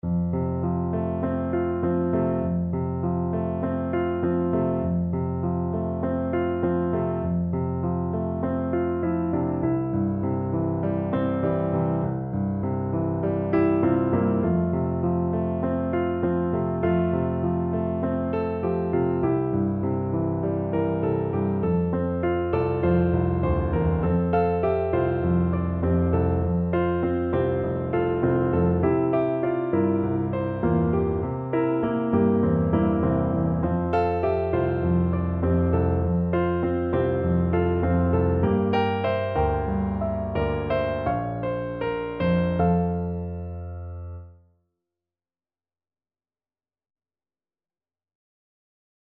Flute
Traditional Music of unknown author.
4/4 (View more 4/4 Music)
Gently Flowing = c.100